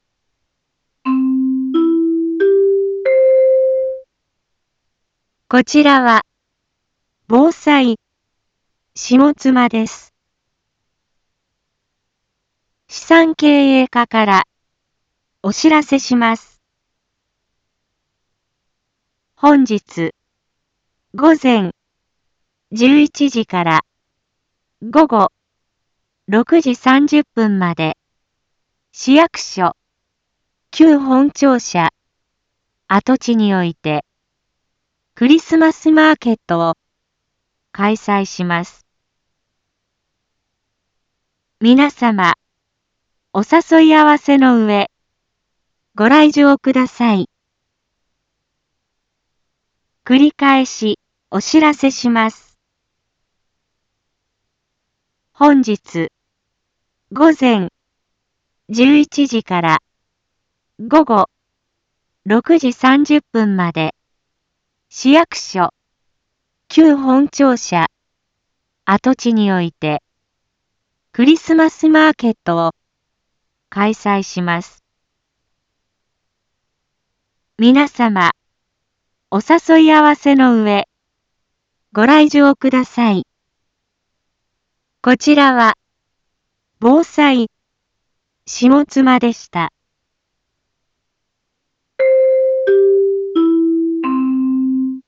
一般放送情報
Back Home 一般放送情報 音声放送 再生 一般放送情報 登録日時：2025-11-29 09:01:56 タイトル：しもつまクリスマスマーケットの開催 インフォメーション：こちらは、ぼうさいしもつまです。